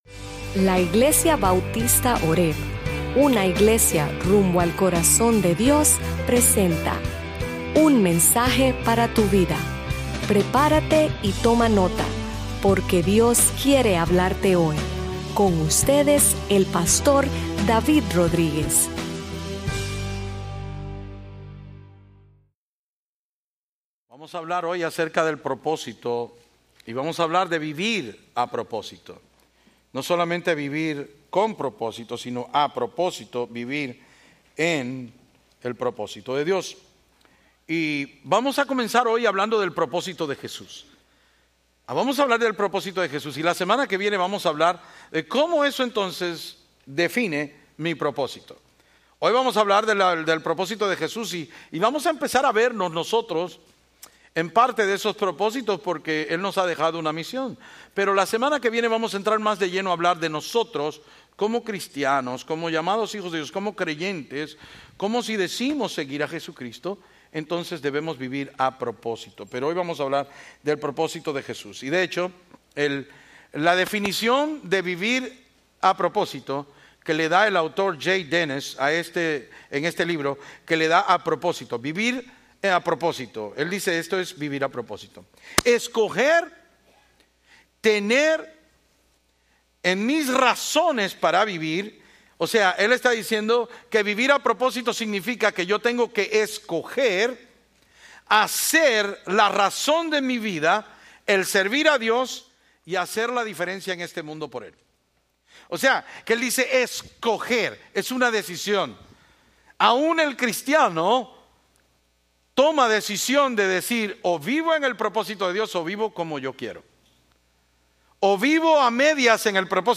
Sermons Archive - Page 112 of 156 - horebnola-New Orleans, LA